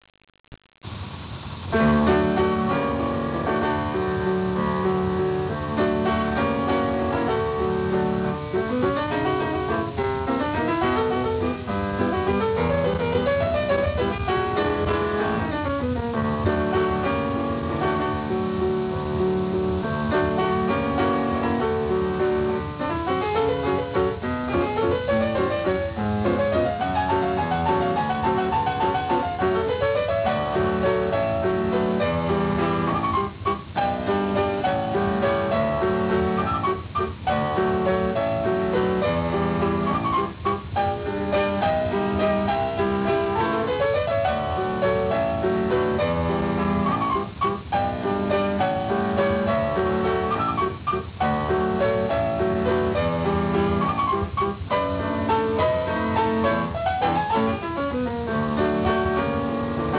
お馴染の曲が、オリジナルの響きを損なわない程度に弾き易い連弾曲として編曲されています。